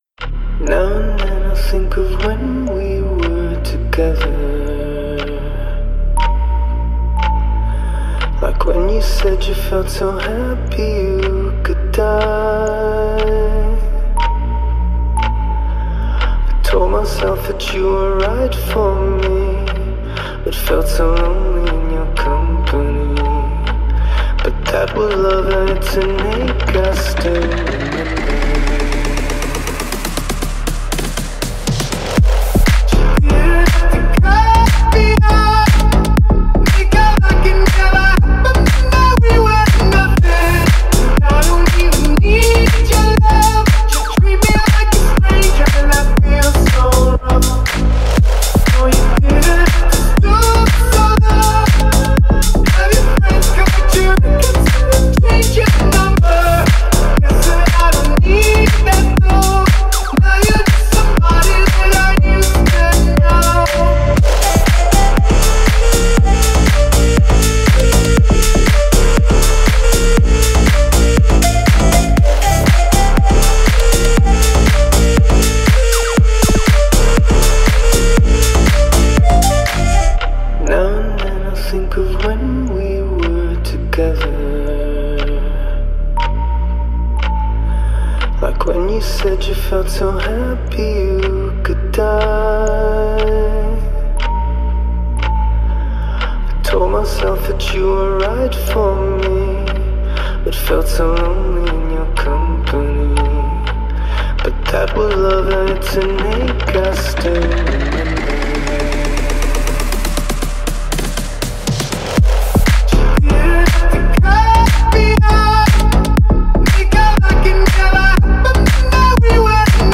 который сочетает в себе элементы инди-попа и электроники.